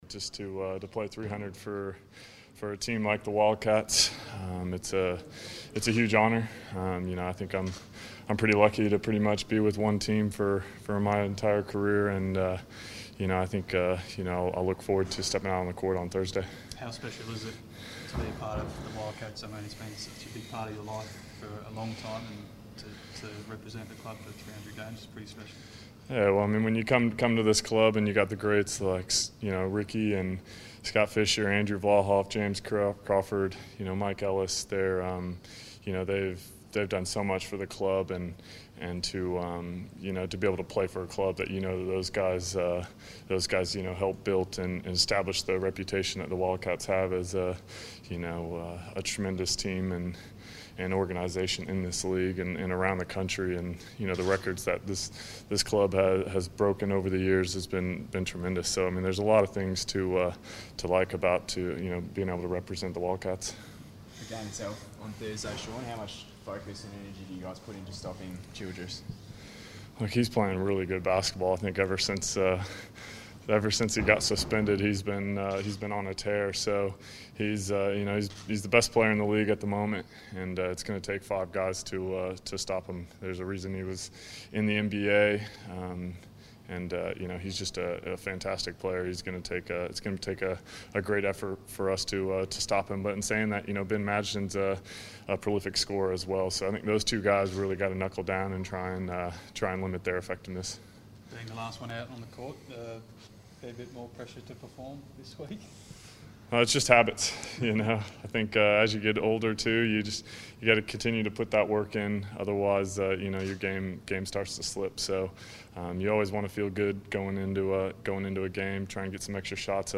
Shawn Redhage press conference - 16 December 2014
Shawn Redhage speaks to the media ahead of playing his 300th game for the Perth Wildcats versus the Sydney Kings.